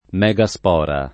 [ m Hg a S p 0 ra ]